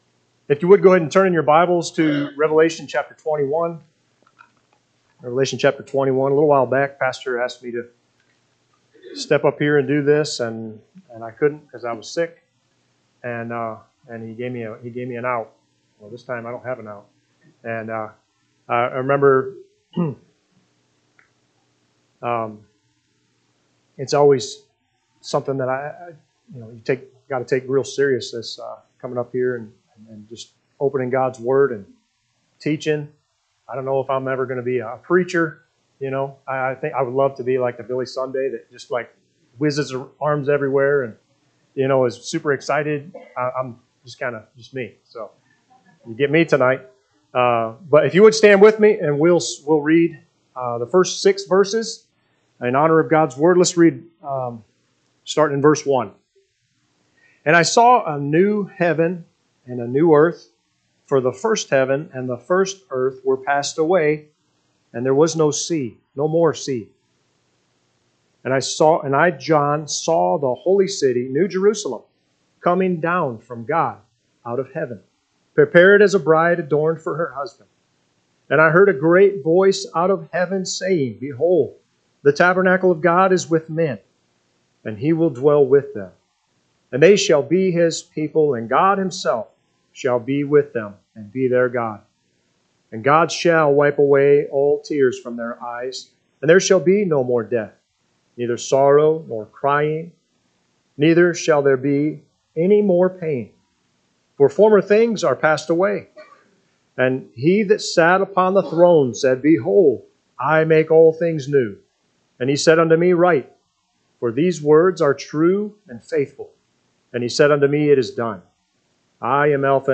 Sunday PM Message